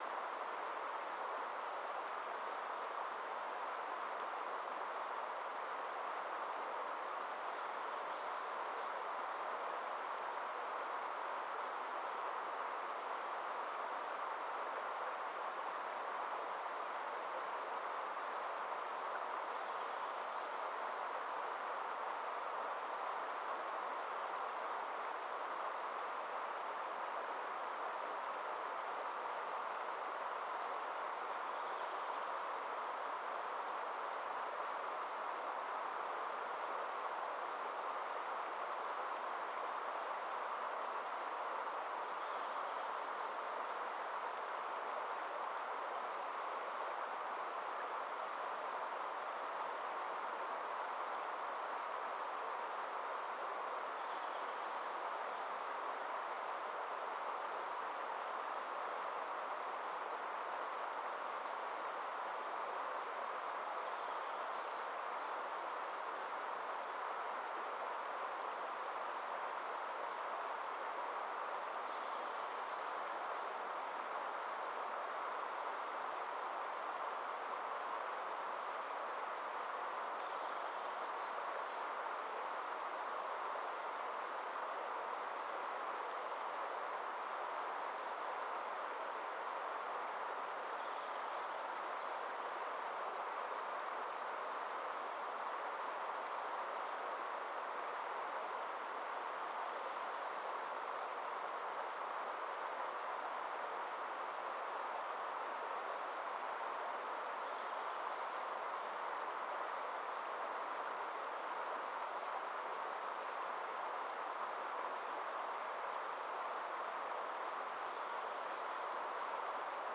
Quellrauschen in Bänder geteilt 1000.wav